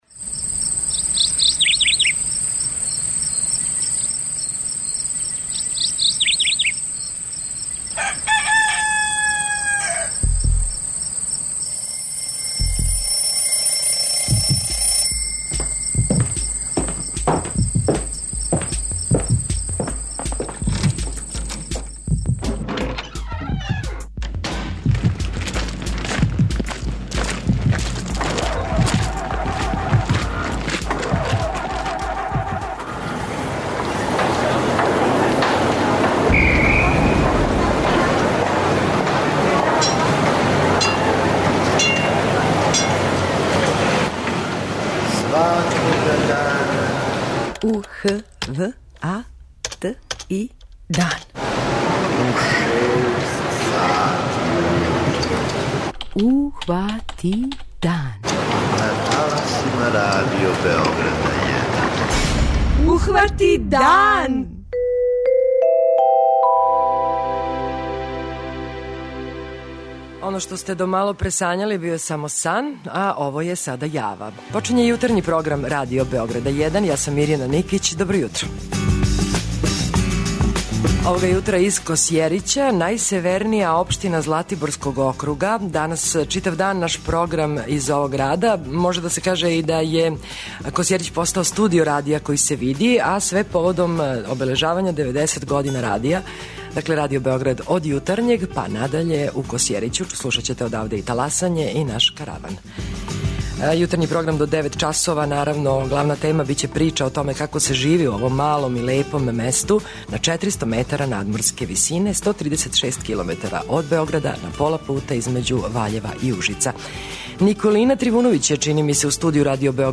Јутарњи програм Радио Београда 1 овог петка емитује се из Косјерића.